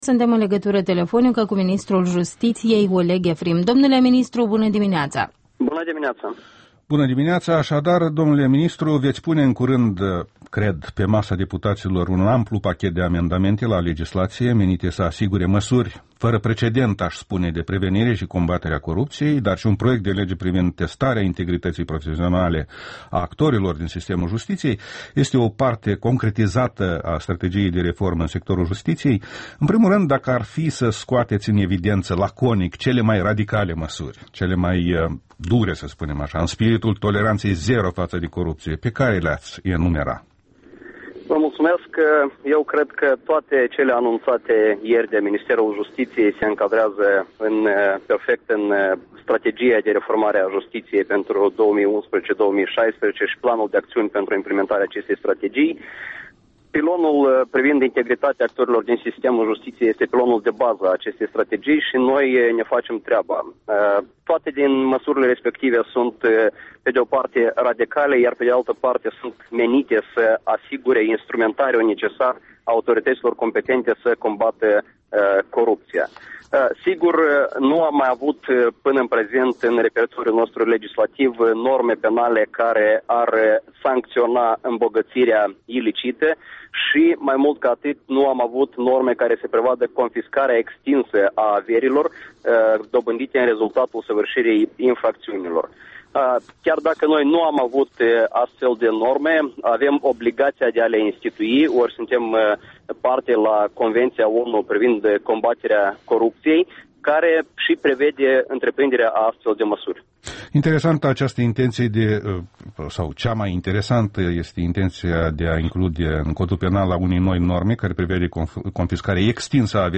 Interviul dimineții la REL: cu Oleg Efrim, ministrul justiției